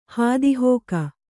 ♪ hādi hōka